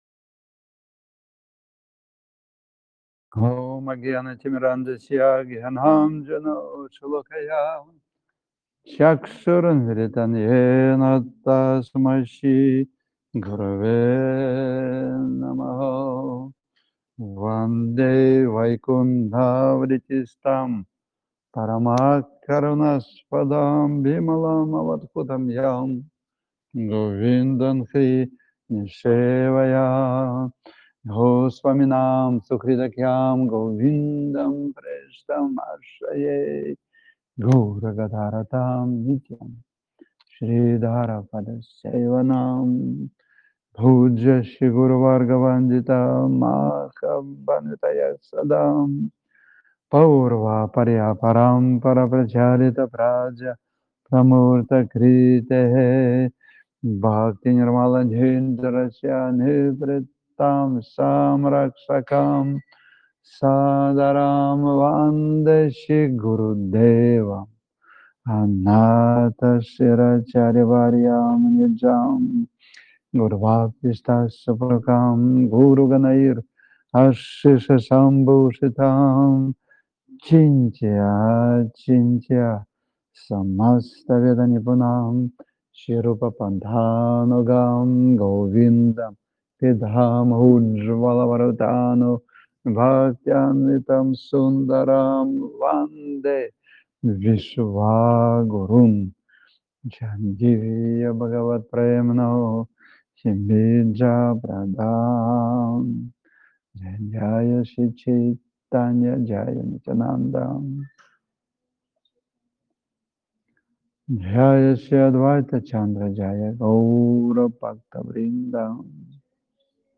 Лахта, Санкт-Петербург
Лекции полностью
Бхаджан